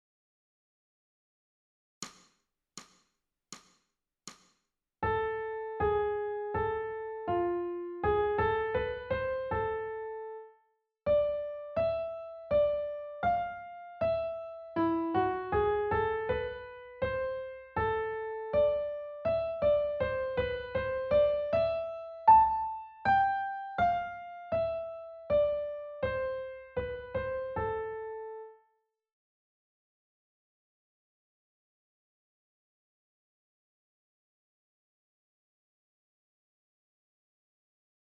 ソルフェージュ 聴音: 1-ii-16